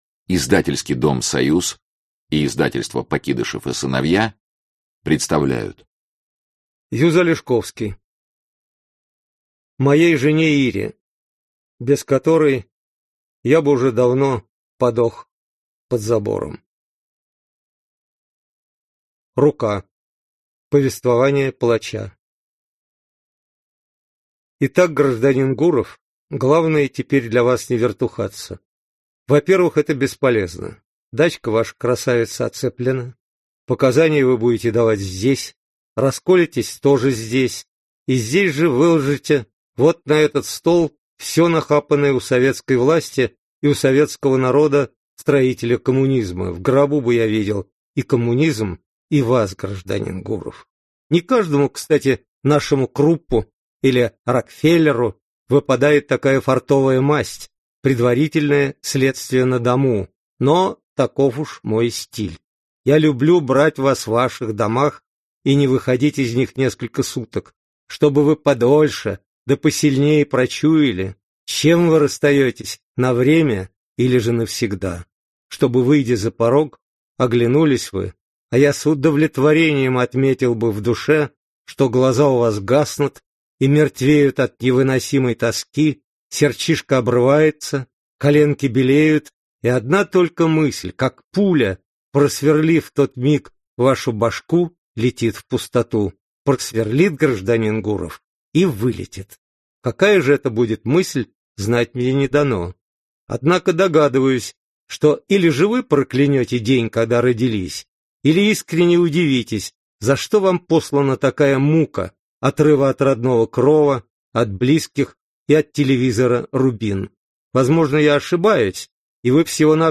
Aудиокнига Рука Автор Юз Алешковский Читает аудиокнигу Юз Алешковский.